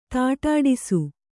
♪ tāṭāṭa